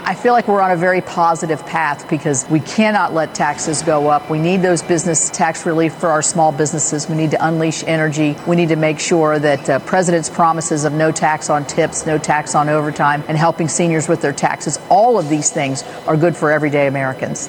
West Virginia Senator Shelley Moore Capito, chair of the Senate Republican Policy Committee, says the bill is moving in a positive direction…